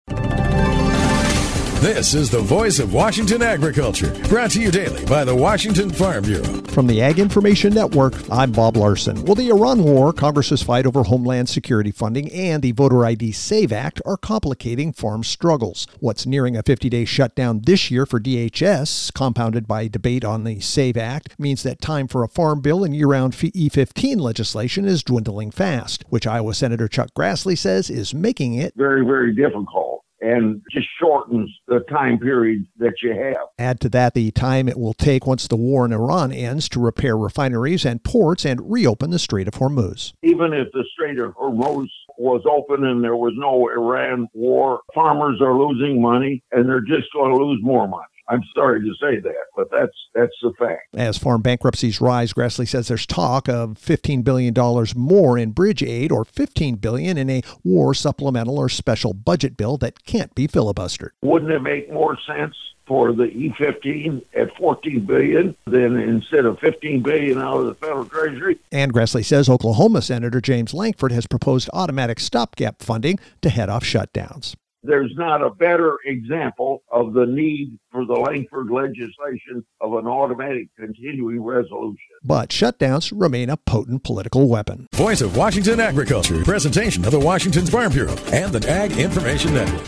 Wednesday Apr 8th, 2026 58 Views Washington State Farm Bureau Report